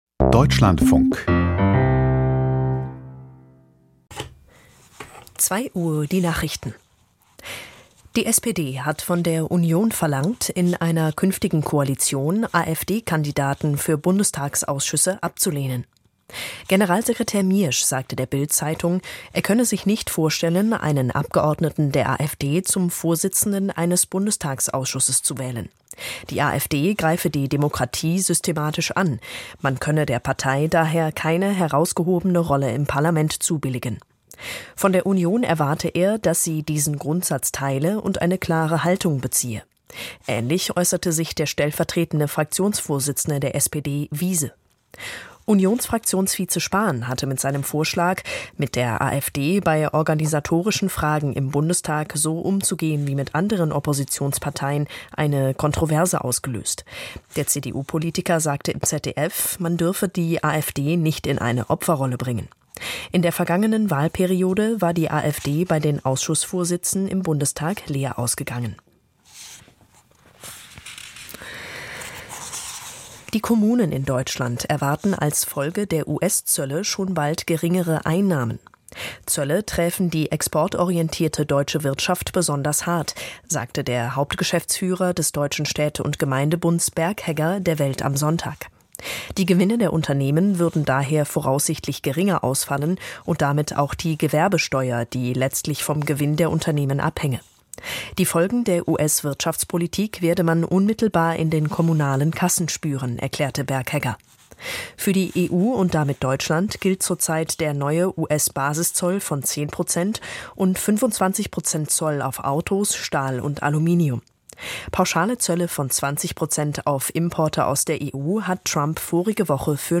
Die Deutschlandfunk-Nachrichten vom 19.04.2025, 02:00 Uhr